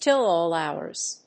アクセントtill áll hóurs